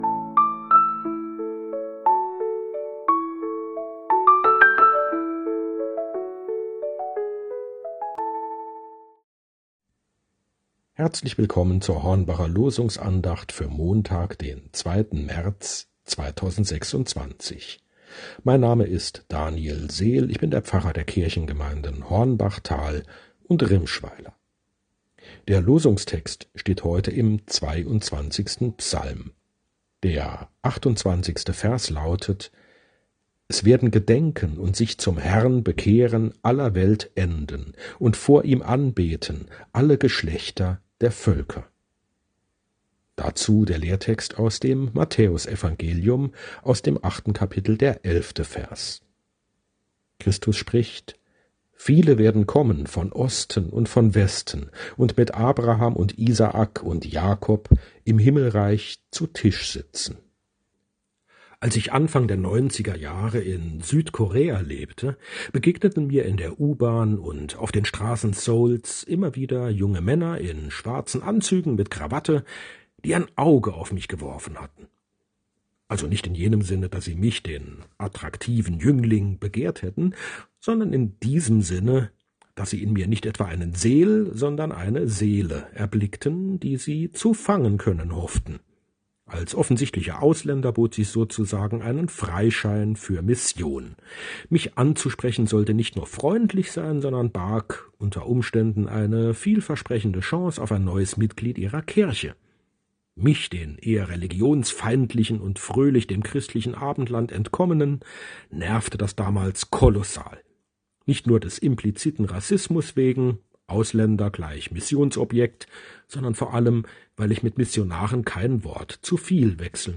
Losungsandacht für Montag, 02.03.2026 – Prot. Kirchengemeinde Hornbachtal mit der prot. Kirchengemeinde Rimschweiler